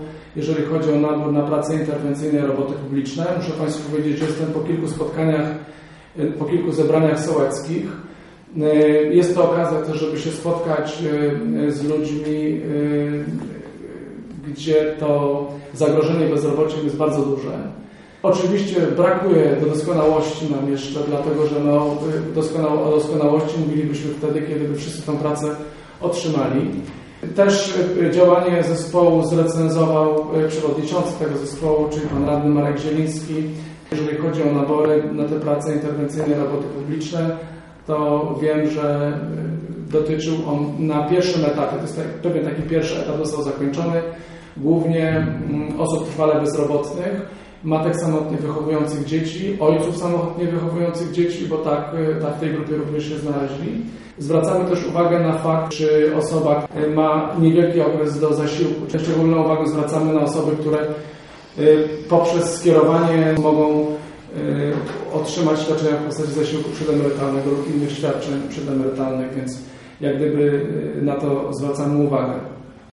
Mówił na wtorkowej konferencji burmistrz Żnina Robert Luchowski.